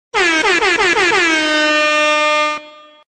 MLG Air Horn